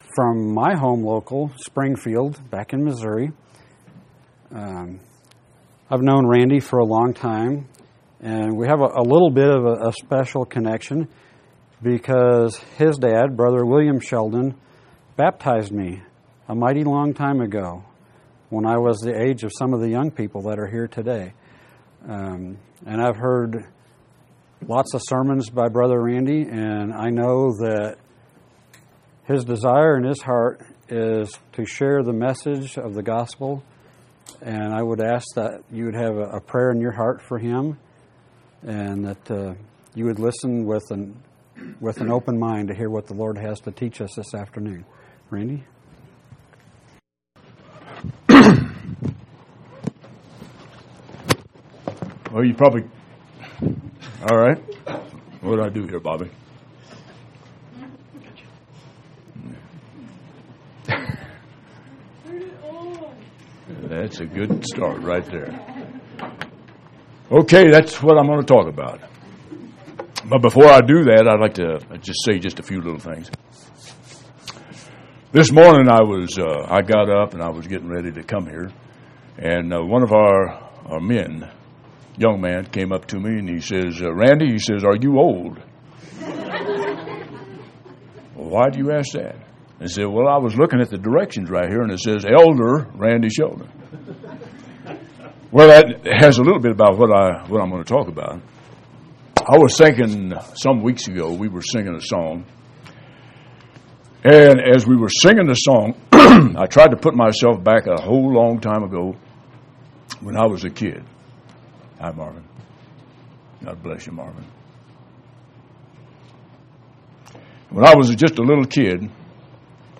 6/10/2016 Location: Colorado Reunion Event